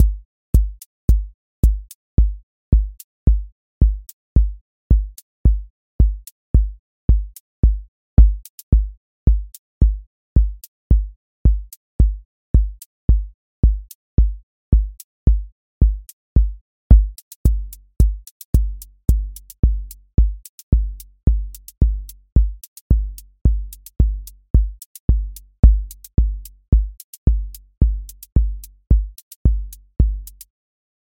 QA Listening Test house Template: four_on_floor
• macro_house_four_on_floor
• voice_kick_808
• voice_hat_rimshot
• voice_sub_pulse